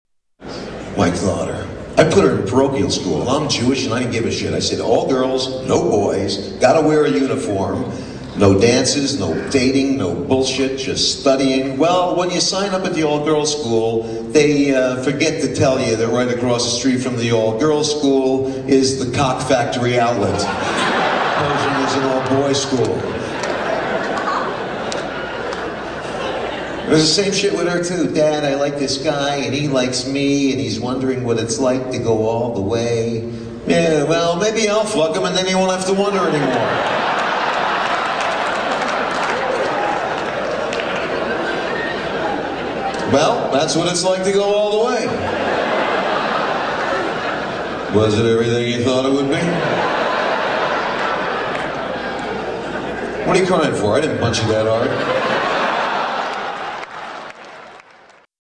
Tags: Comedian Robert Schimmel clips Robert Schimmel audio Stand-up comedian Robert Schimmel